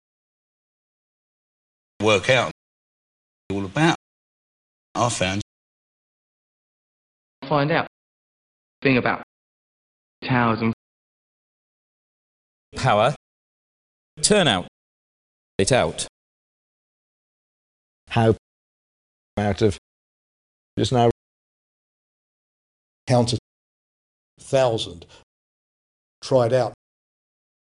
Example 2: The London or home counties SBE MOUTH vowel: [æɔ]-[æ:]
Three examples each by five speakers, in the same order as Example 1: